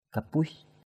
/ka-‘puɪs/ (d.) con beo = panthère. anâk kapuis anK kp&{X con beo. kapuis anâk kp&{X anK beo con.
kapuis.mp3